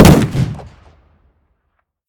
shotgun-shot-1.ogg